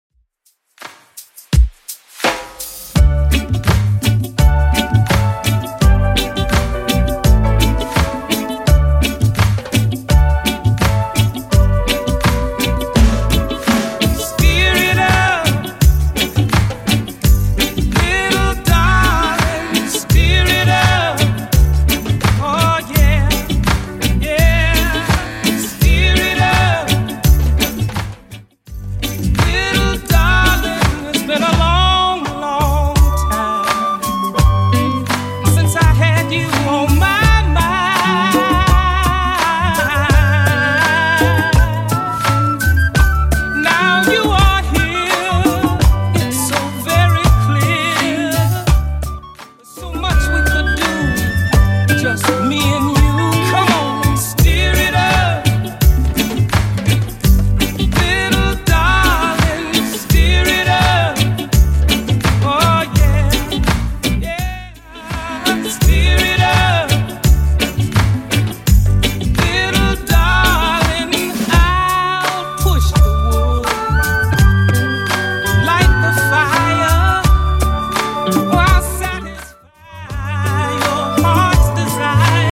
Genre: 80's
BPM: 102